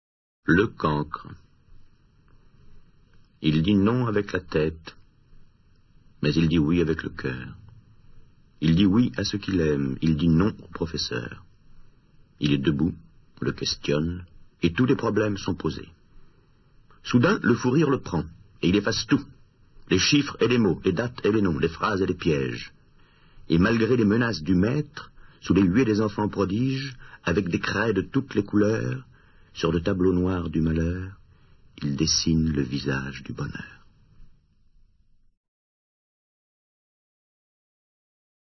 dit par Serge REGGIANI